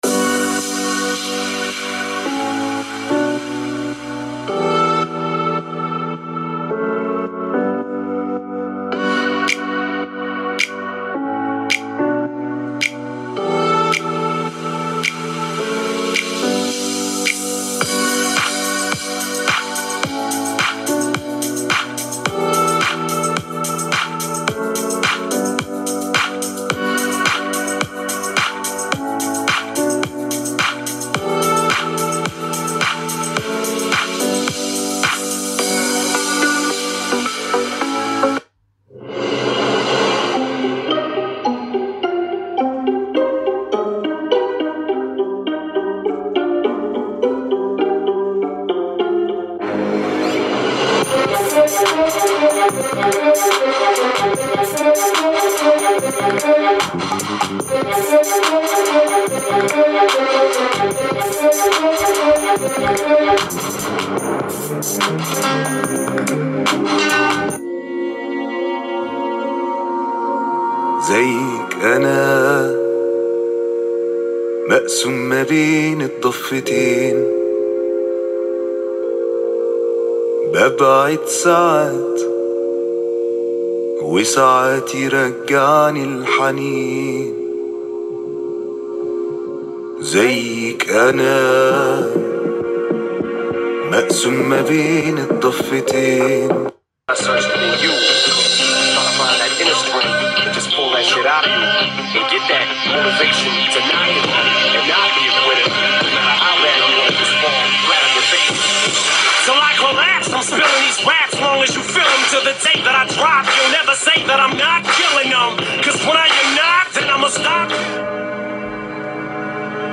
الإزعاج و صوت السماعات
الجهاز يقدم سماعات مدمجة بقوة  2x2w مع علو صوت مرتفع يقدر ب 85 ديسيبل و صوت السماعات جيد جدا سواء مع أستخدام برنامج NAHIMIC الملحق او بدون و سأترك لكم تجربة صوتية للجهاز ولكن مع تفعيل البرنامج!
التجربة الصوتية مع تفعيل تقنيات NAHIMIC
MSI-VECTOR-17-HX-Speaker-Sound-Test.mp3